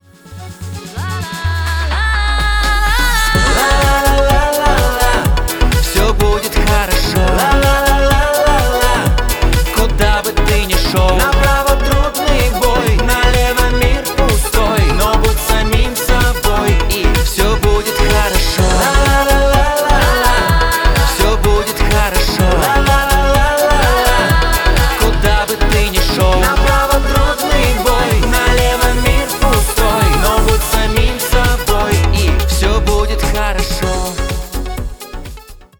• Качество: 320, Stereo
поп
позитивные
зажигательные
веселые
добрые